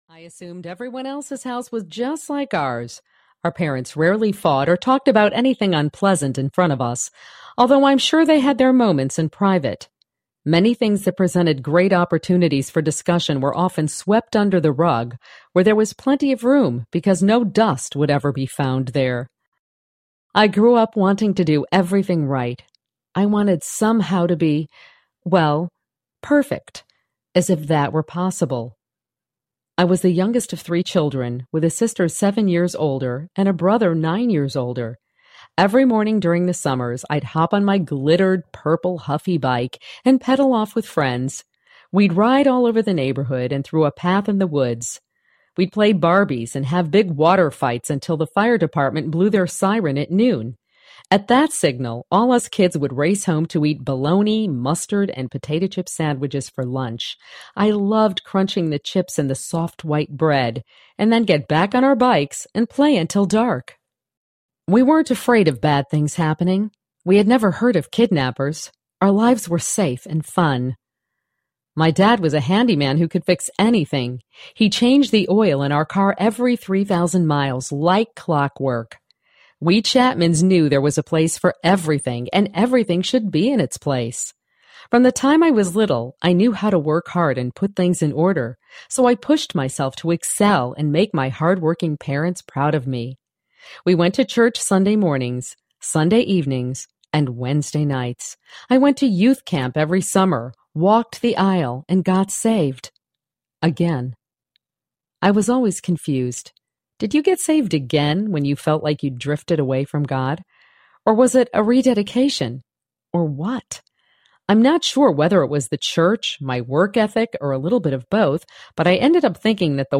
Choosing to SEE Audiobook
5.9 Hrs. – Abridged